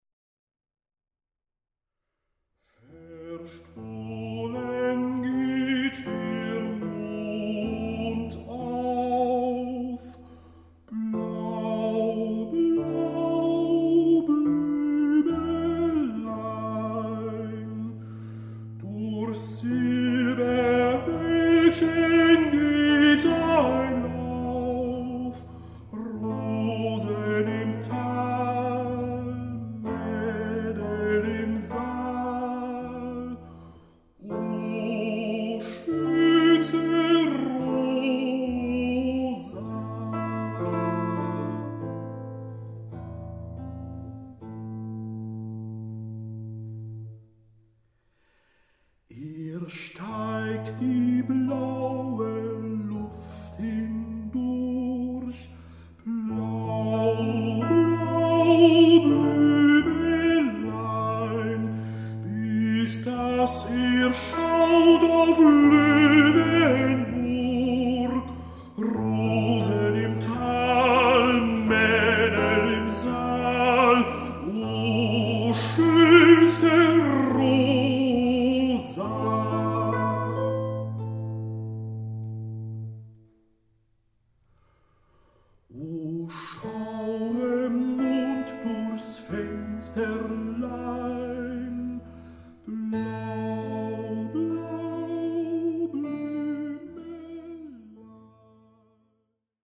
ALTE VOLKSLIEDER
Die Lieder werden schlicht von der Gitarre begleitet.